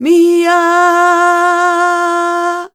46b07voc-a#m.wav